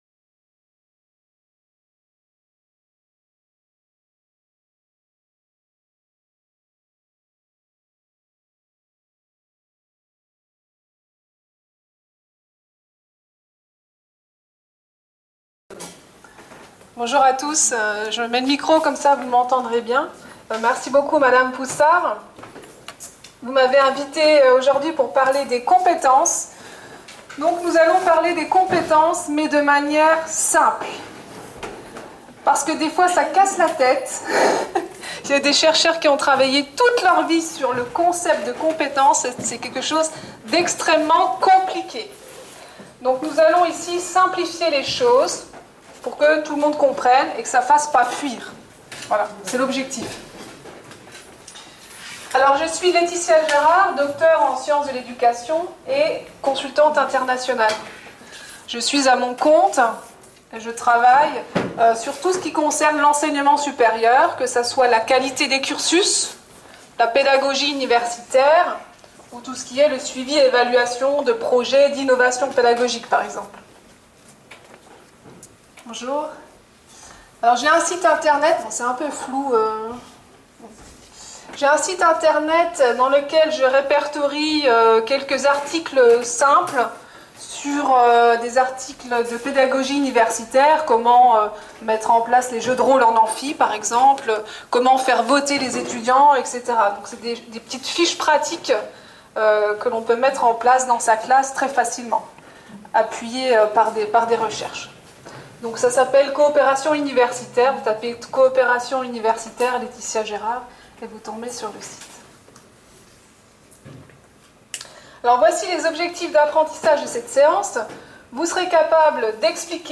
La conférence a permis d'aborder les points suivants : - L'alignement pédagogique, - Qu'est-ce qu'une compétence ? - Comment développer la compétence, - Comment évaluer la compétence, - Comment mettre en place l'approche par compétence. Le séminaire est ouvert à la formation Inter U des personnels enseignants et enseignants-chercheurs.